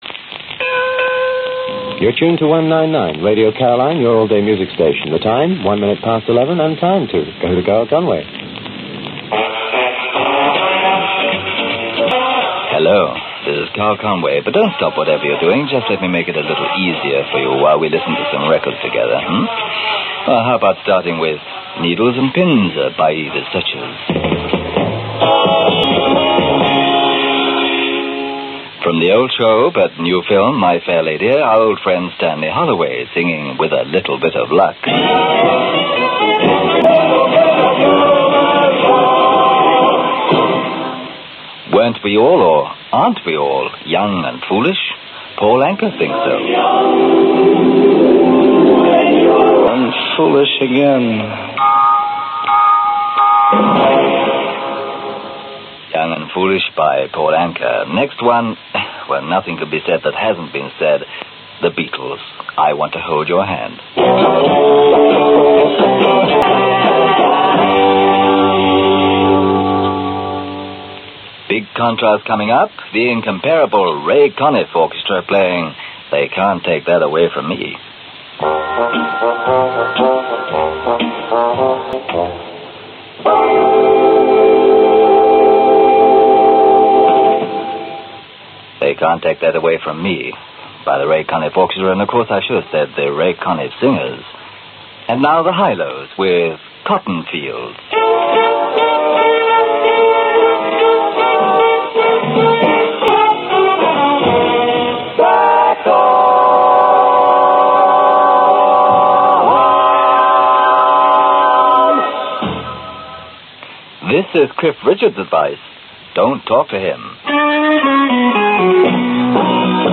An early example of Radio Caroline’s programming with Simon Dee
Note the varied mix of music played at that time - May 1964.